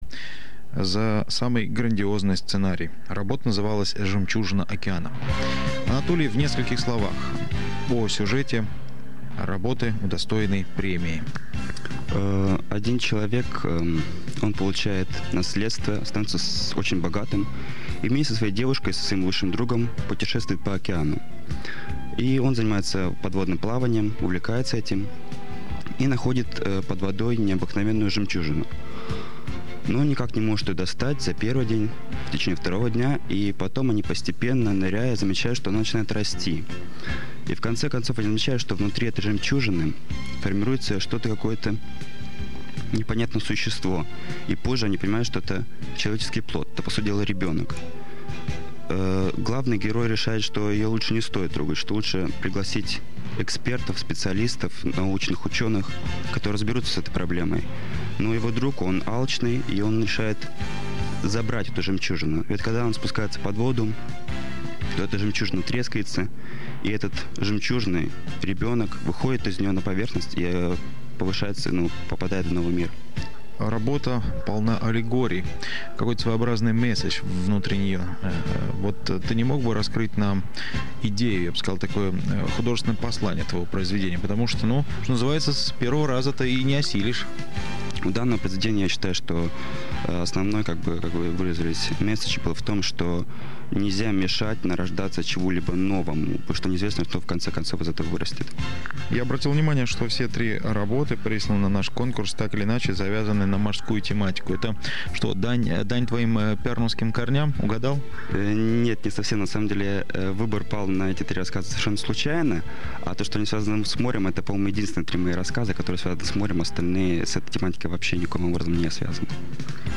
Во время записи я сильно волновался, и это ясно ощущается по тому, как я отвечал на задаваемые вопросы.
Первое интервью. Первая часть - 2 мин 10 сек; 2,57 МB